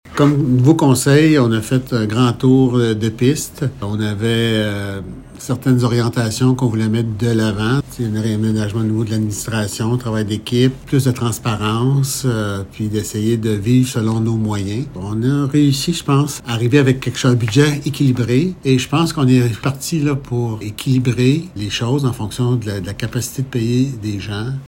Il s’agit du premier budget réalisé par le nouveau conseil municipal suite aux élections municipales du 2 novembre dernier. Le maire explique que c’était l’occasion de mettre en place certaines grandes orientations pour les années à venir :